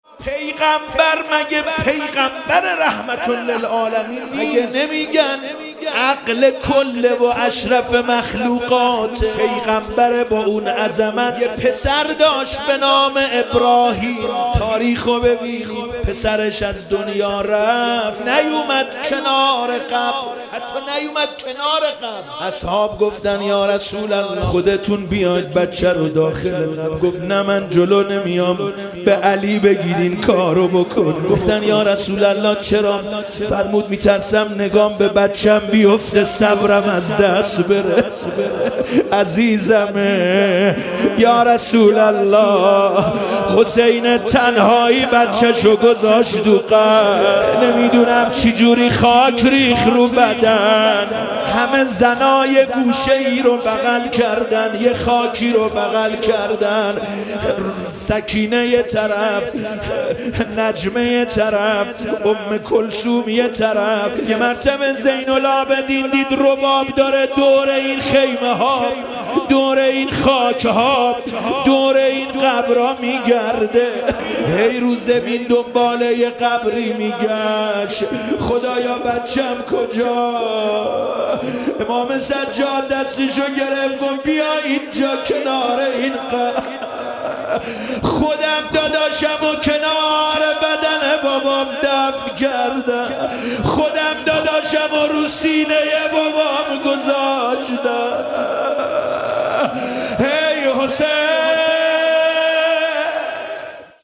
موکب الشهدا ساوجبلاغ